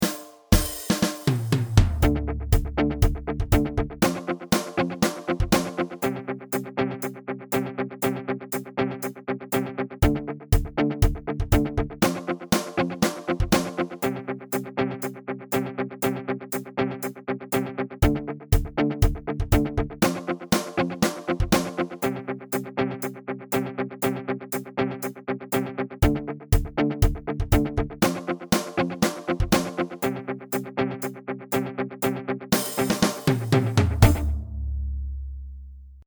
Free Bucket Drumming Beats
• An audio backing track to play along with in class